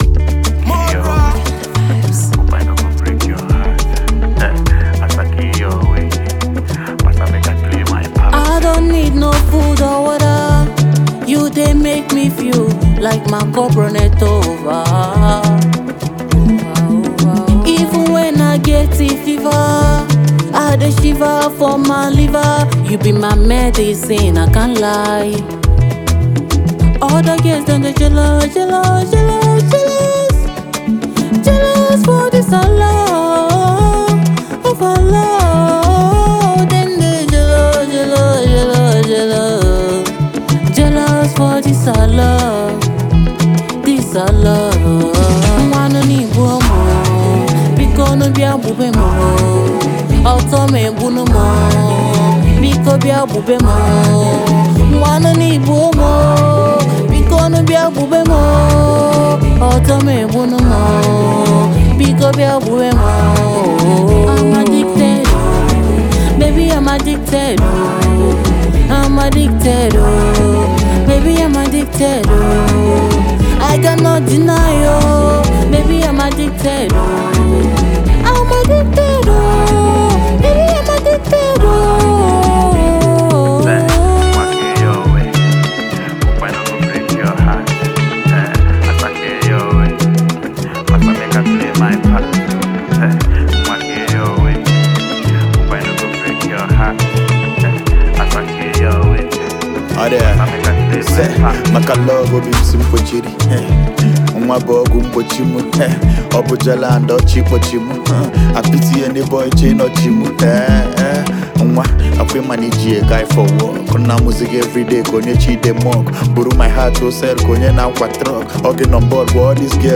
Afro-fusion
duet